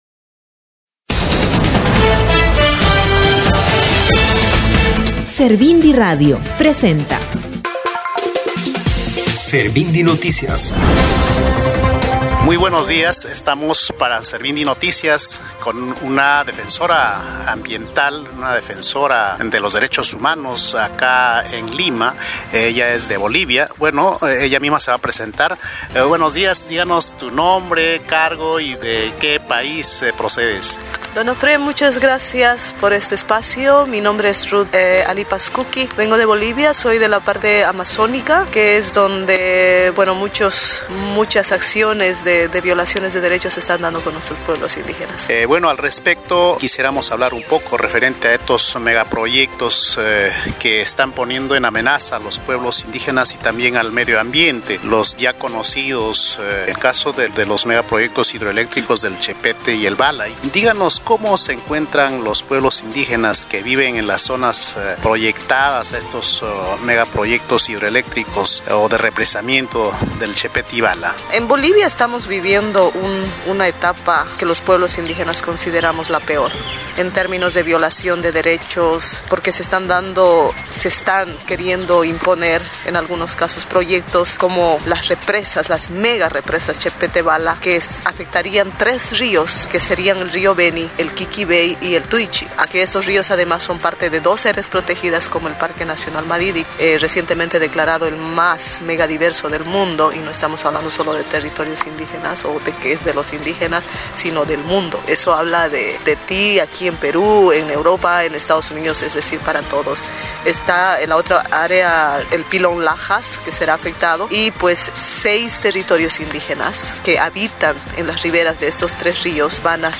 — Quisiéramos hablar un poco referente a estos megaproyectos que están poniendo en amenaza a los pueblos indígenas y también al medio ambiente por los ya conocidos megaproyectos hidroeléctricos del Chepete y el Bala.